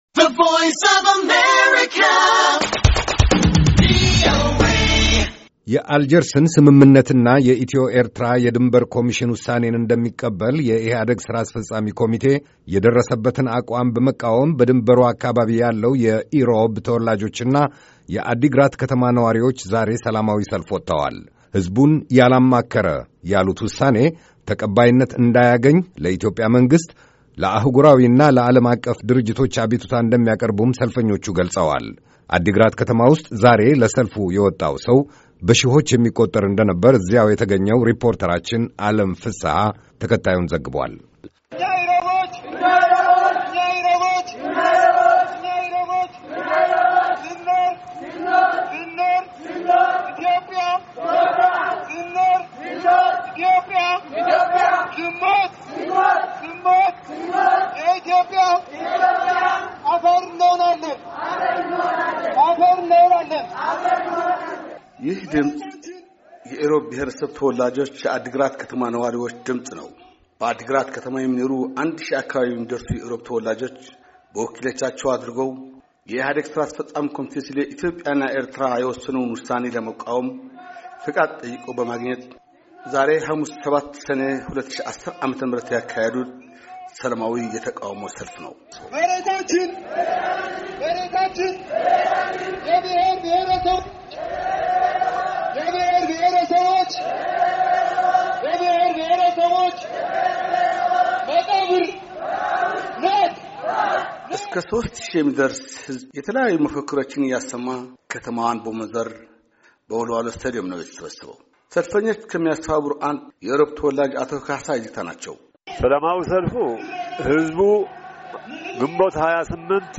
አዲግራት ከተማ ውስጥ ዛሬ ለሰልፍ የወጣው ሰው በሺዎቹ የሚቆጠር እንደነበር እዚያው የተገኘው ሪፖርተራችን